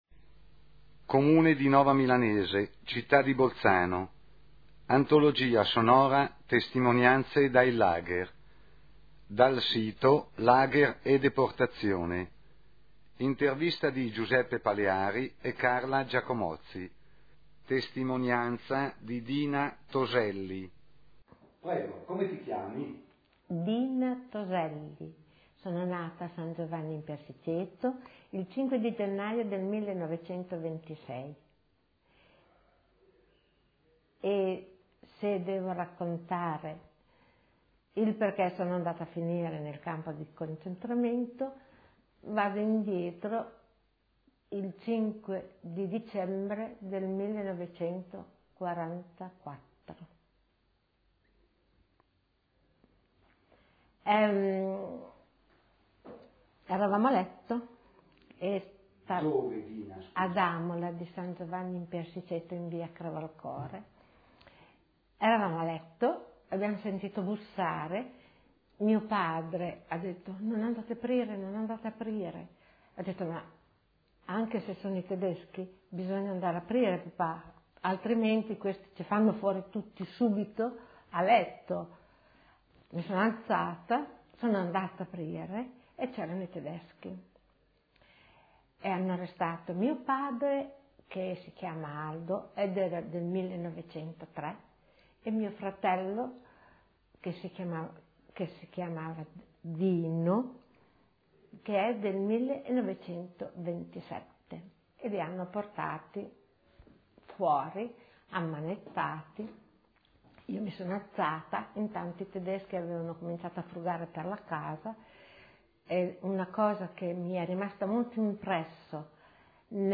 Intervista del